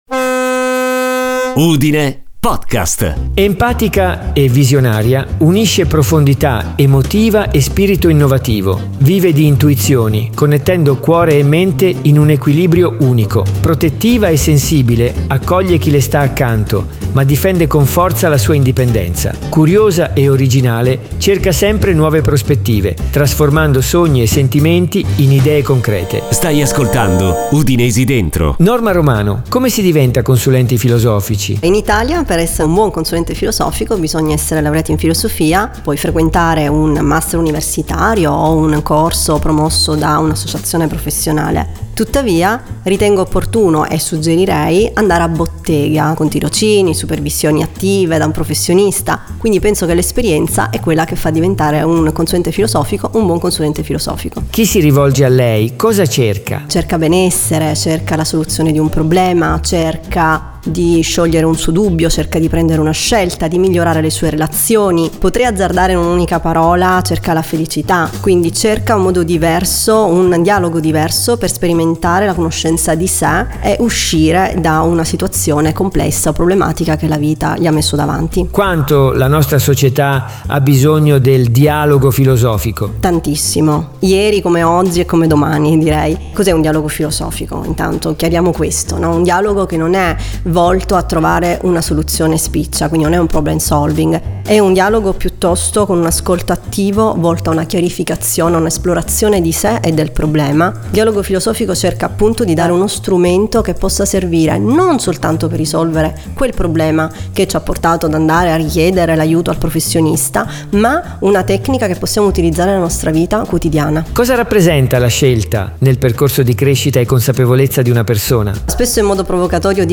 la voce della sigla